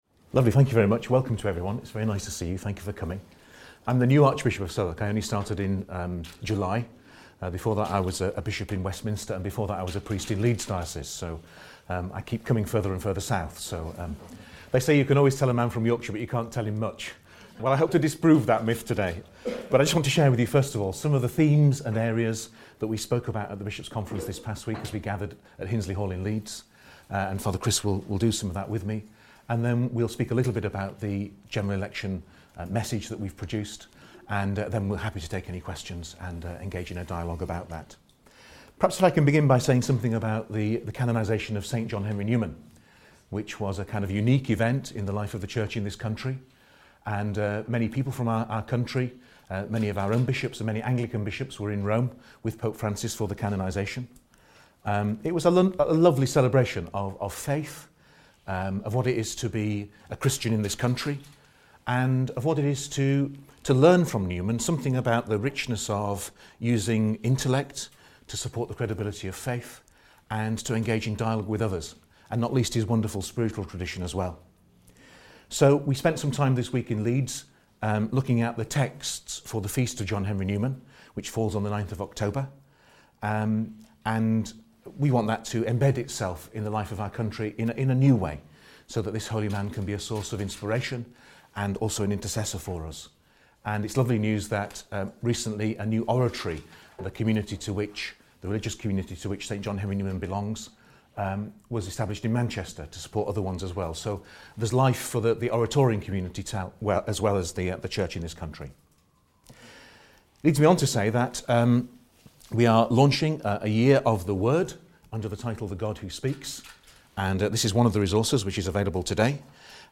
Plenary Press Conference - General Election 2019
Archbishop John Wilson, the Catholic Archbishop of Southwark, spoke to journalists assembled in London for the post-Bishops' plenary meeting press conference.